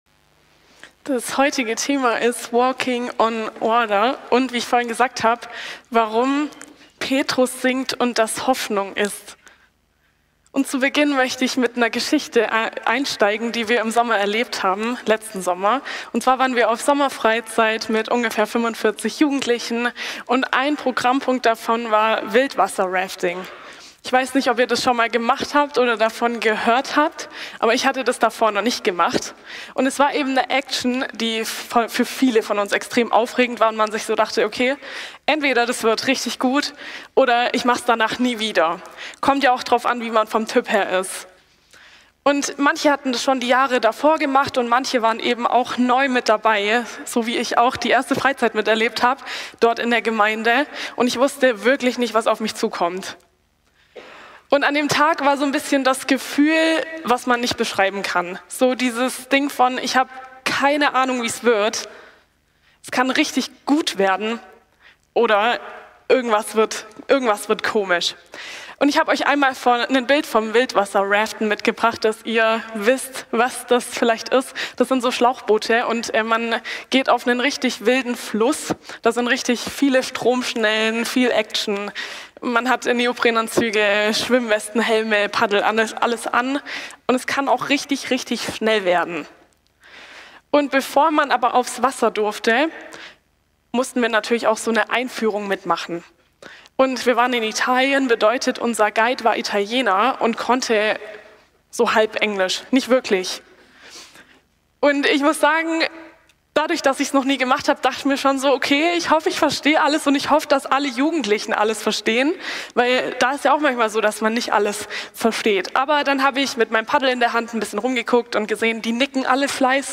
Januar 2026 Walking on Water – Warum Petrus sinkt – und genau das Hoffnung ist Typ: Predigt Walking on Water Warum Petrus sinkt – und genau das Hoffnung ist „Walking on water“ klingt nach dem Außergewöhnlichen.